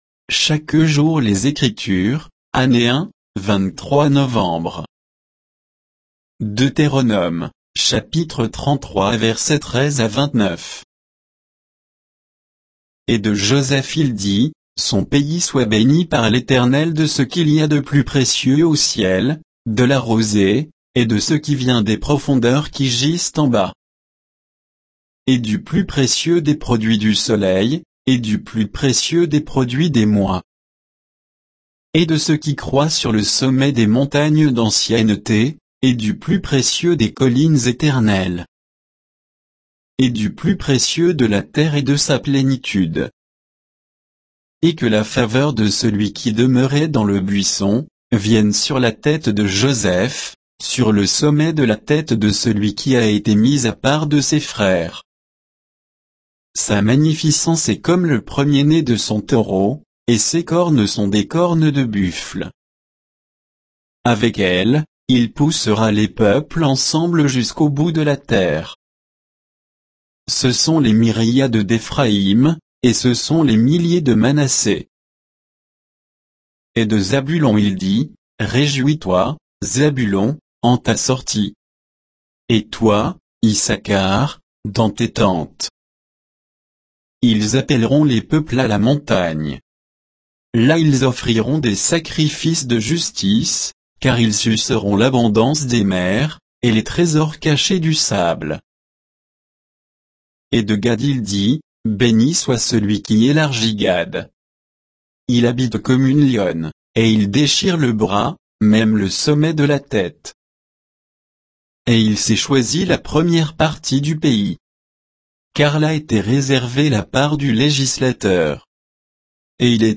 Méditation quoditienne de Chaque jour les Écritures sur Deutéronome 33, 13 à 29